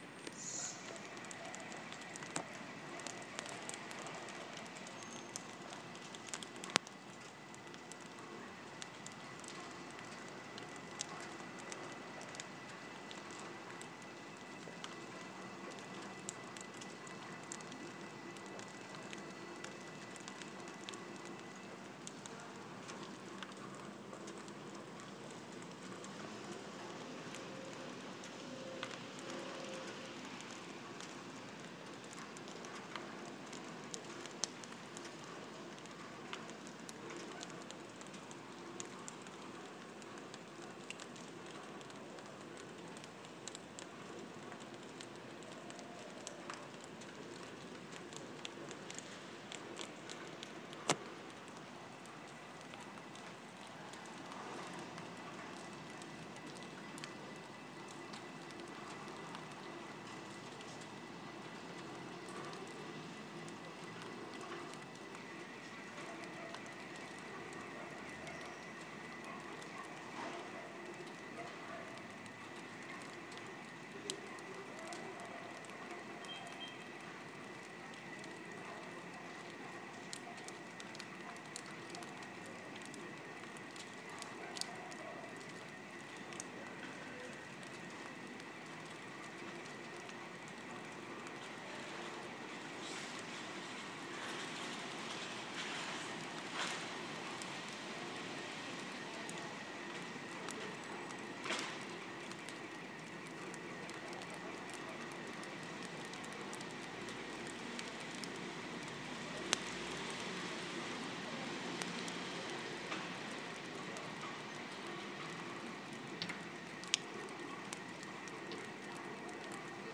Manhã de domingo no Centro